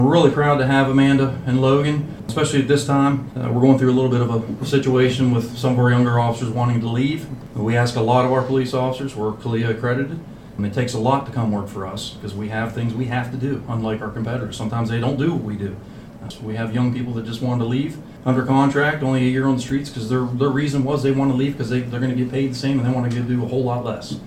Two new Cumberland City Police officers were introduced at Tuesday’s Mayor and Council public meeting.